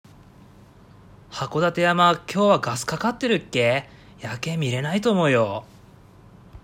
そこで、読者の皆様によりリアルに「はこだて弁」を体感していただきたく、全ての例文の音声収録をしました！